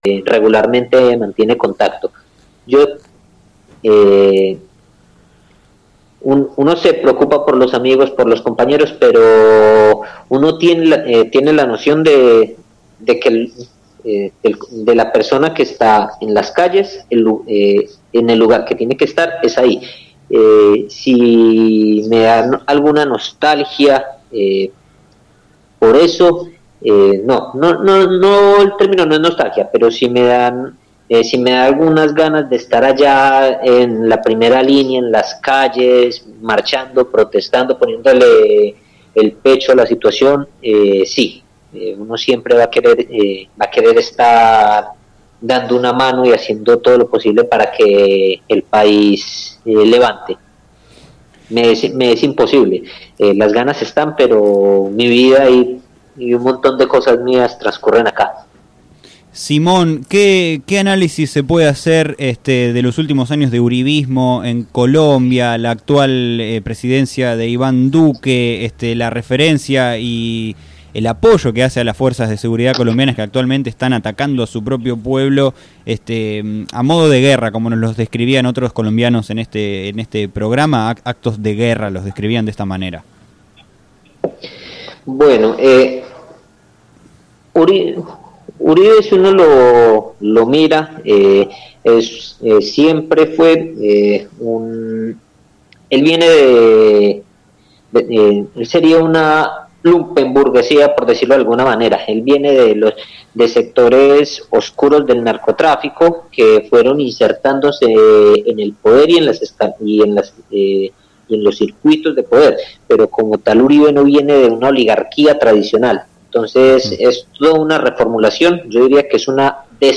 En diálogo con el programa “Sobre las Cartas la Mesa” de FM Líder 97.7